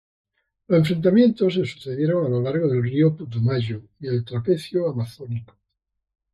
Pronounced as (IPA) /ˈrio/